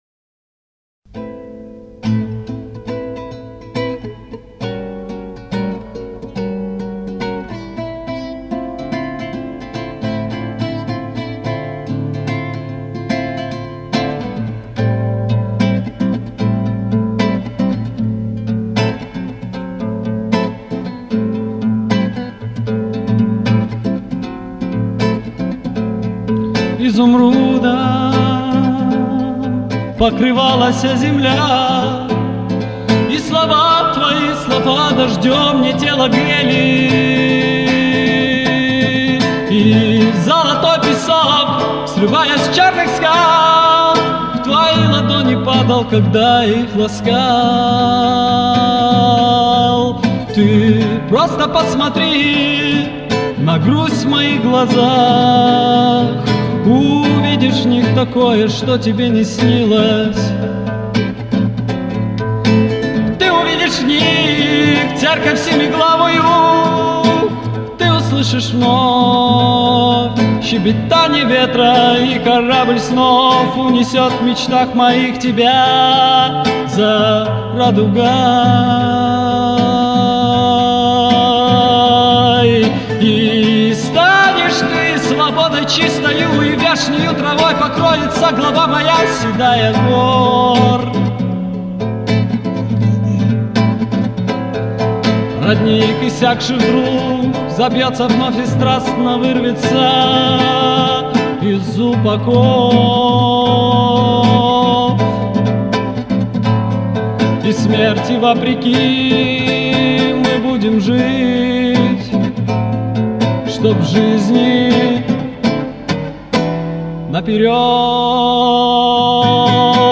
Демоальбом - г. Москва